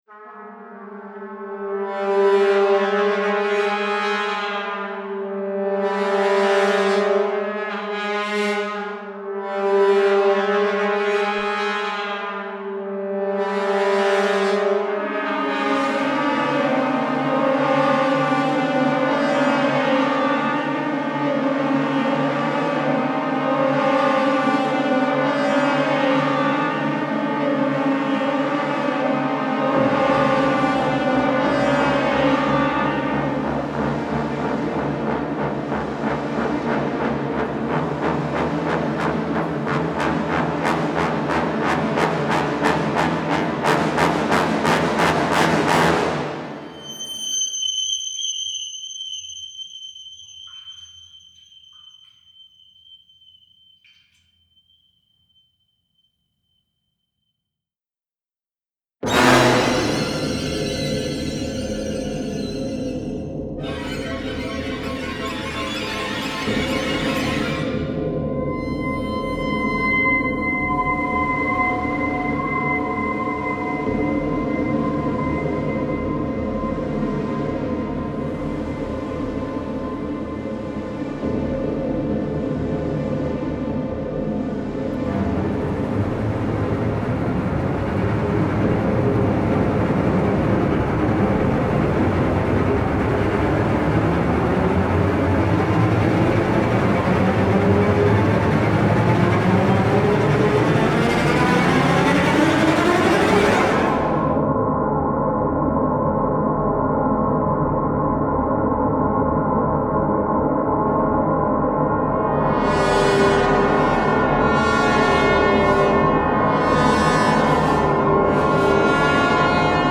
Style Style Orchestral, Soundtrack
Mood Mood Dark, Scary
Featured Featured Brass, Strings